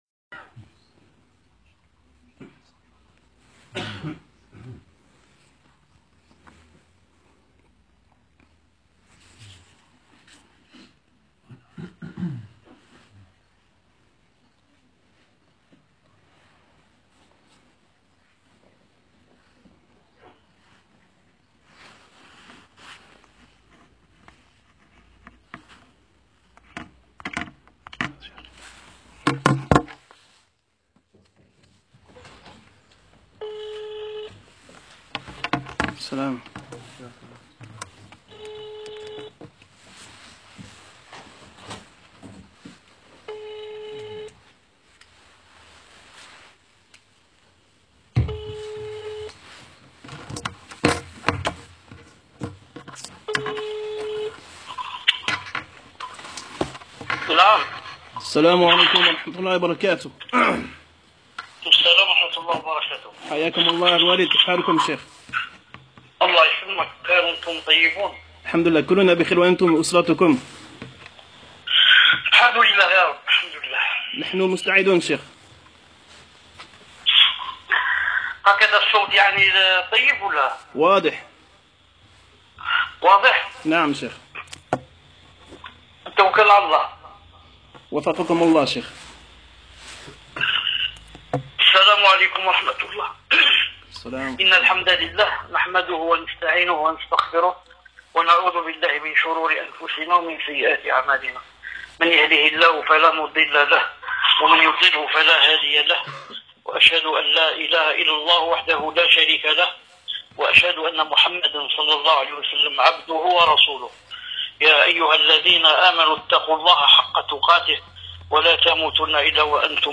Cette nassiha a été enregistrée en direct de son salon 0o0 Apprendre Lislam Sur Le Vrai Minhaj 0o0...